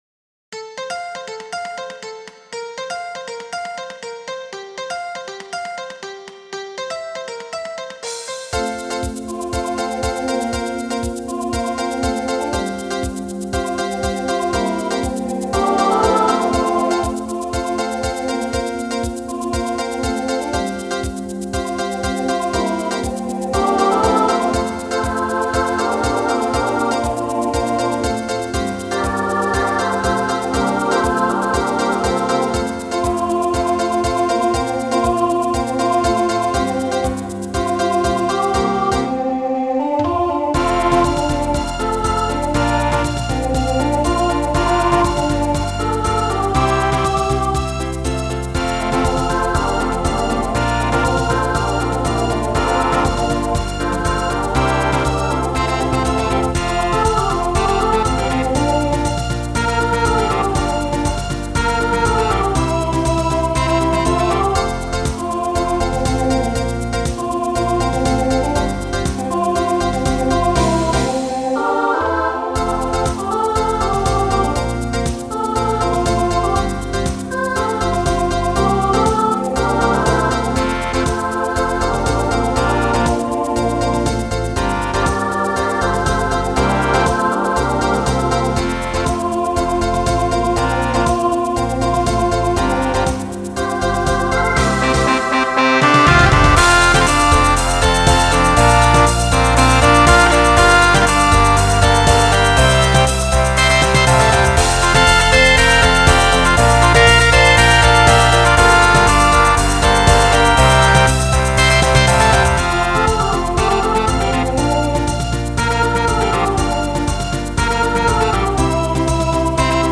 Voicing SSA Instrumental combo Genre Pop/Dance
Mid-tempo